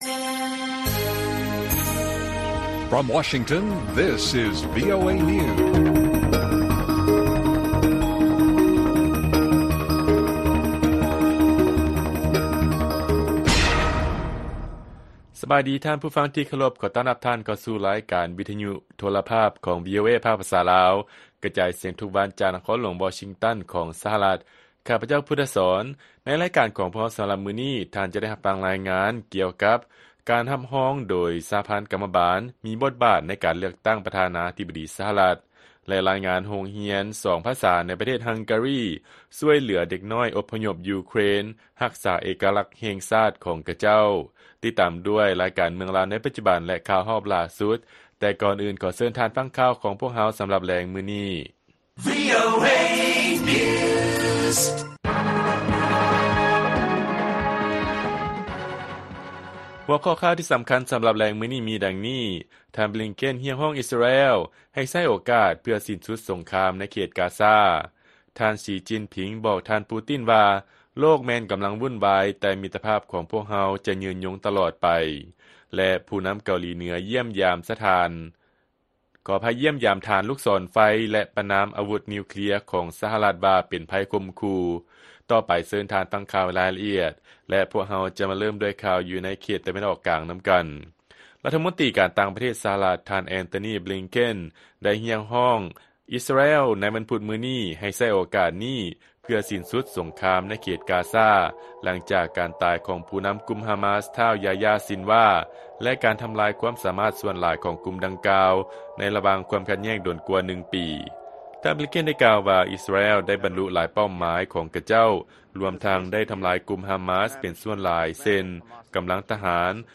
ລາຍການກະຈາຍສຽງຂອງວີໂອເອລາວ: ທ່ານ ບລິງເຄັນ ຮຽກຮ້ອງ ອິສຣາແອລ ໃຫ້ໃຊ້ໂອກາດເພື່ອສິ້ນສຸດສົງຄາມໃນ ກາຊາ